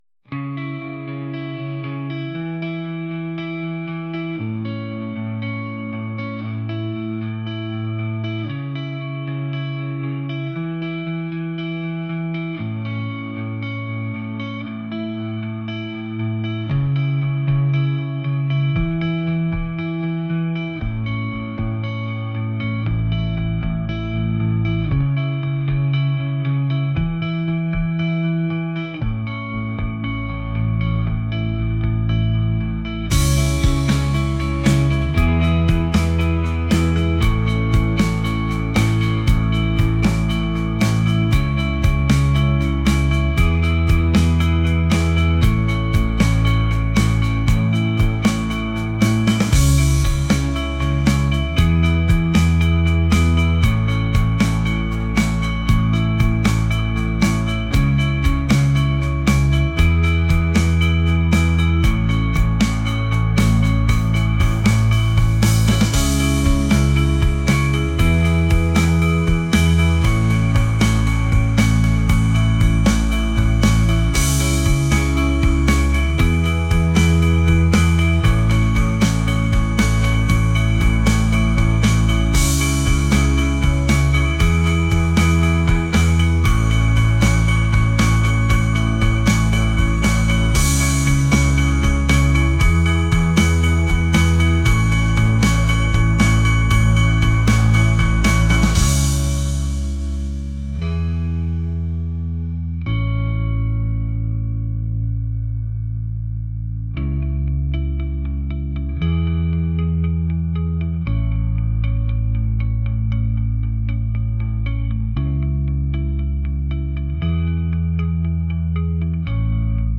indie | acoustic | folk